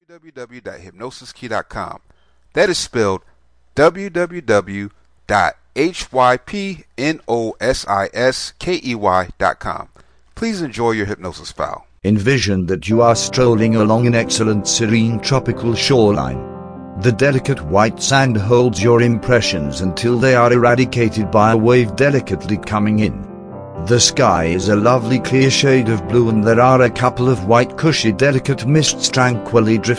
Welcome to Self Authority Self Hypnosis Mp3, this is a powerful hypnosis script that helps you have more self control over yourself.